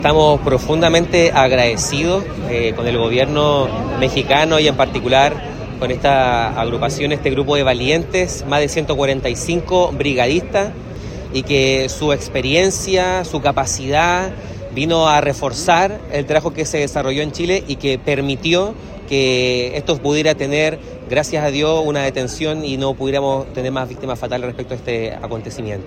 Por ello, se llevó a cabo una ceremonia en agradecimiento y despedida de los brigadistas.